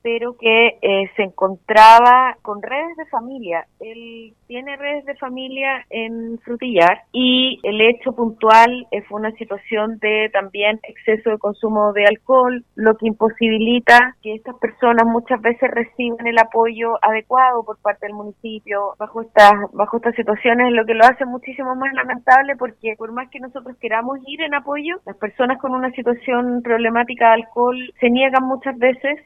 La seremi de Desarrollo Social, Soraya Said, se refirió a la red familiar que acompañaba al hombre y puntualizó que el consumo excesivo de alcohol en éste tipo de personas limita el trabajo que se pueda realizar con ellos.